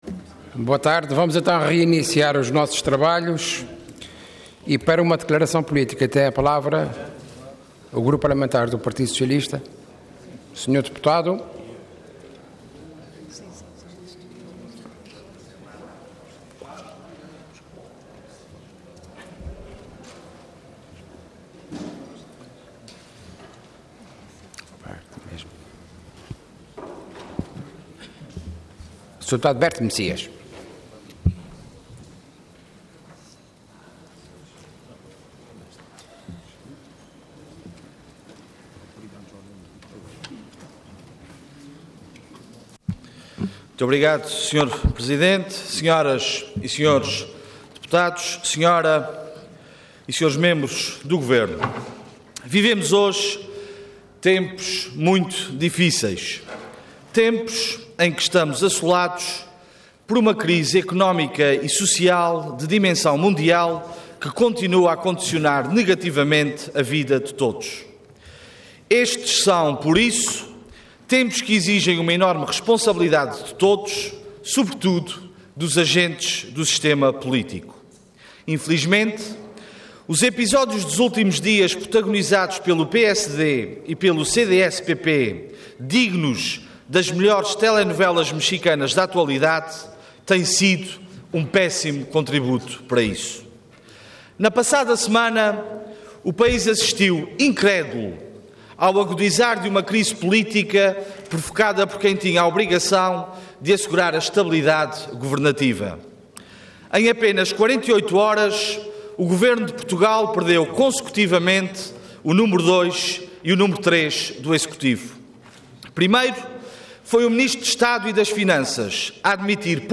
Intervenção Declaração Política Orador Berto Messias Cargo Deputado Entidade PS